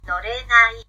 no re na i